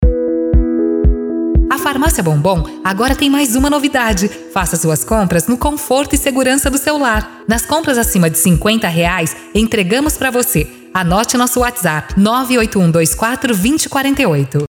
Anúncios Personalizados com Locutores Profissionais
Spot-Zap-Farmacia-Bombom.mp3